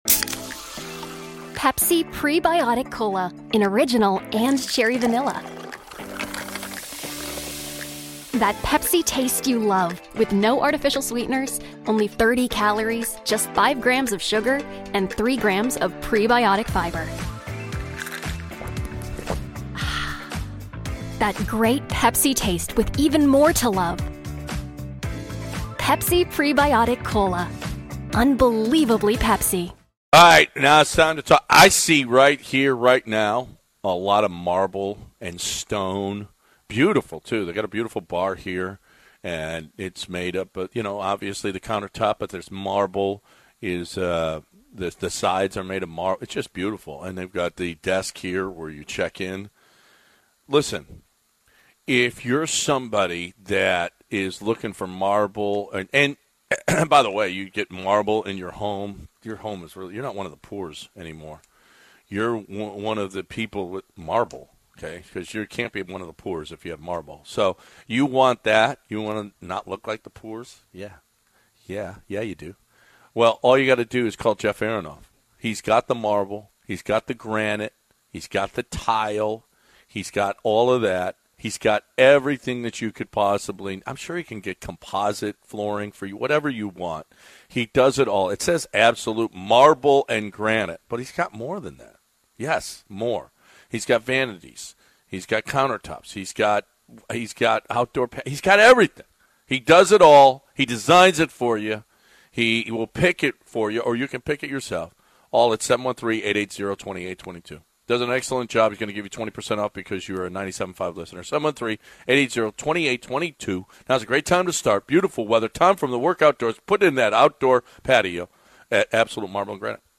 They start the second hour speaking to a local ref and NFL calls and technology. They talk about Zlatan Ibrahimovic and his overconfidence. They talk a little Astros. Sister Jean joins the show.